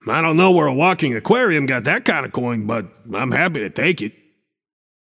Shopkeeper voice line - I don’t know where a walking aquarium got that kind of coin, but, I’m happy to take it.